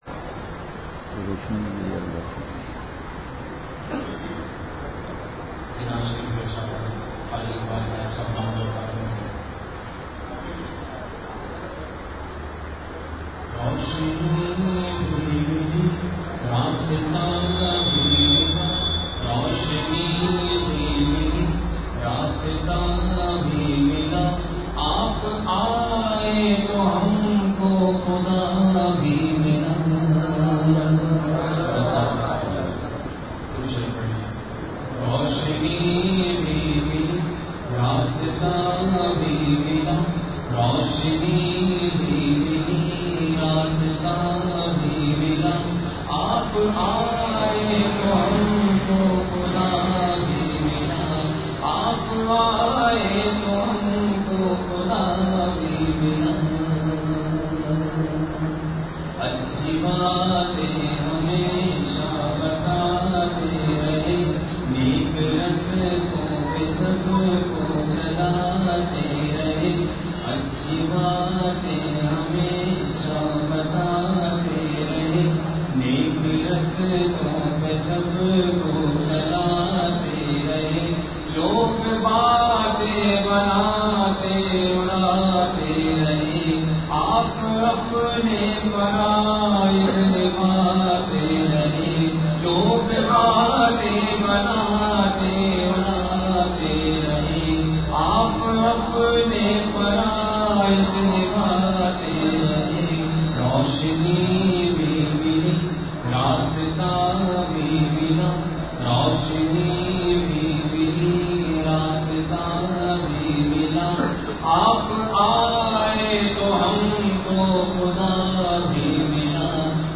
بیان ۱۷۔اپریل ۲۰۱۹ء : غصہ کا علاج اور جذب کے واقعات ،جامعہ مسجد سبحانی ایکسپرس !